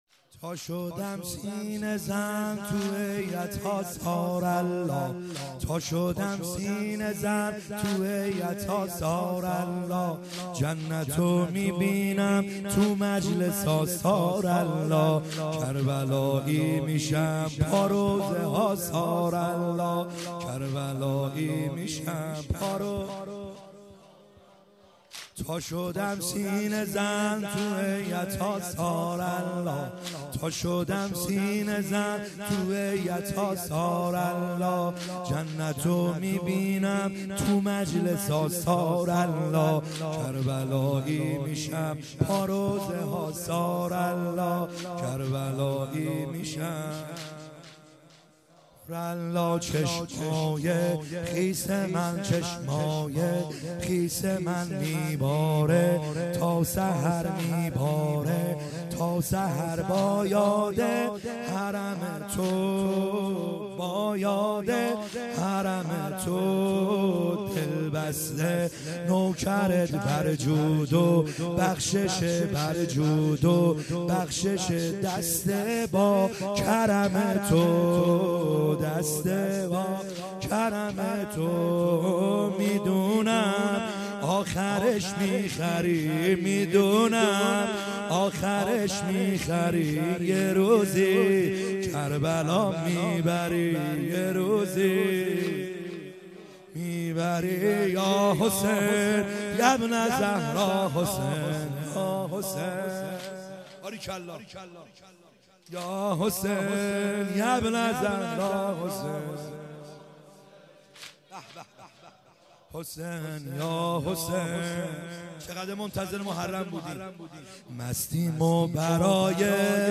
خیمه گاه - بیرق معظم محبین حضرت صاحب الزمان(عج) - واحد | تا شدم سینه زن